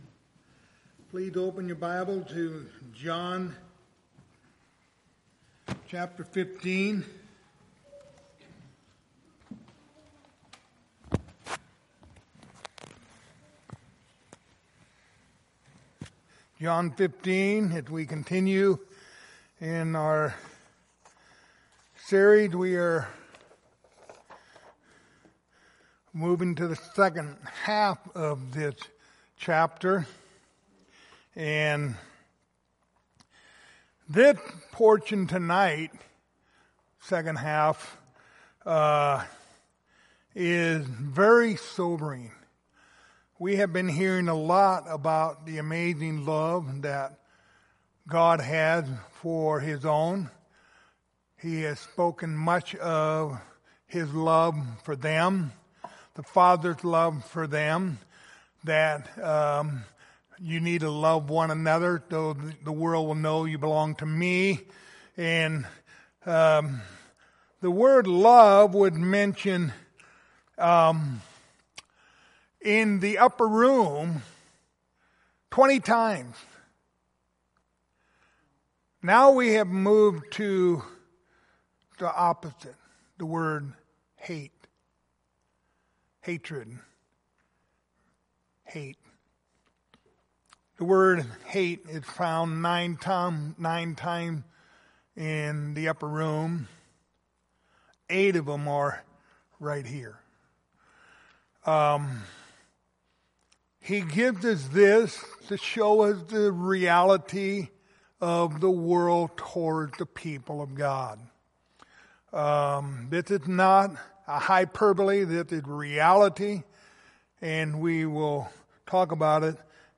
Passage: John 15:13-21 Service Type: Wednesday Evening